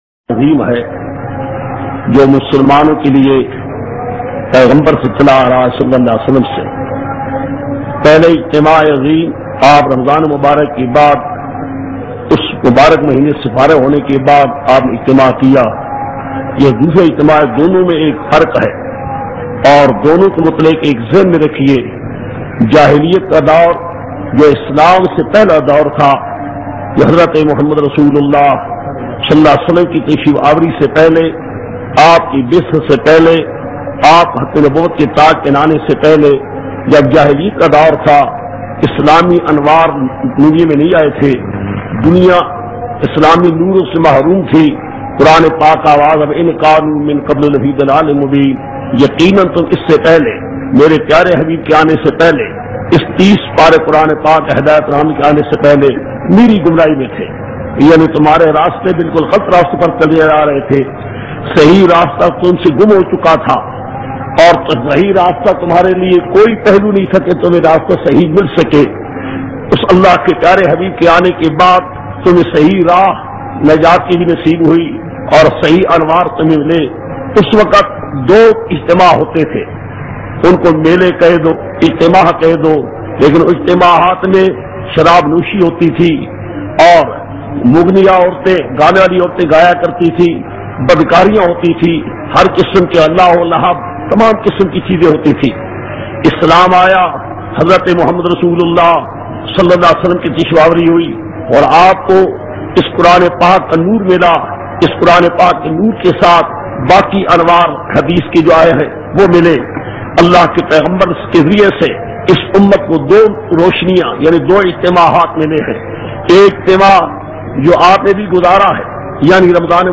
Eid ul Azha khutba